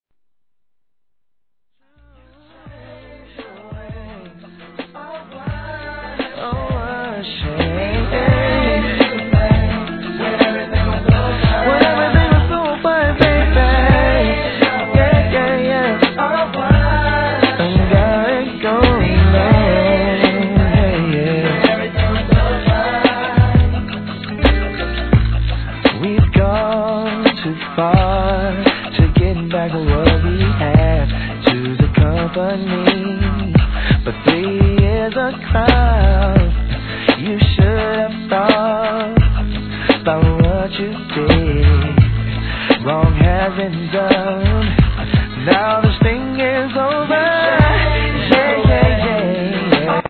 HIP HOP/R&B
GOODミディアムR&B!!!